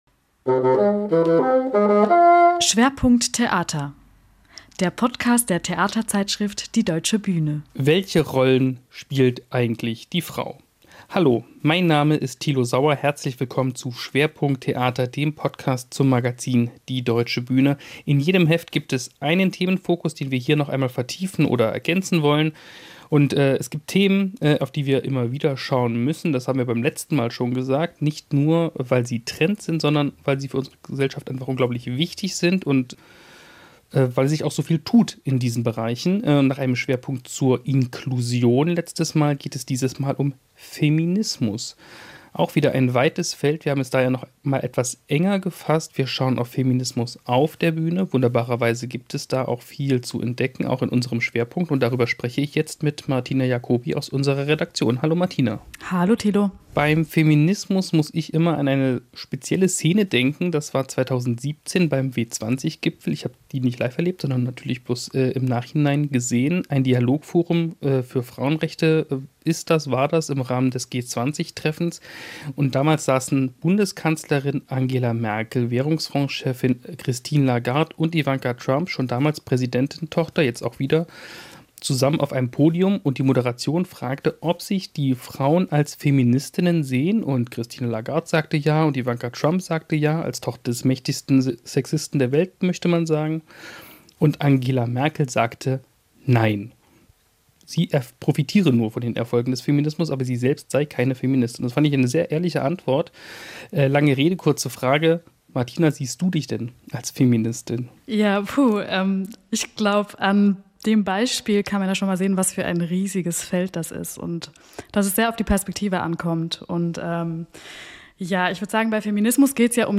Welche Rolle die Neu-Zusammensetzung von Werken dabei spielt und warum Humor auf Kosten marginalisierter Gruppen nicht mehr geht, erklärt sie im Gespräch.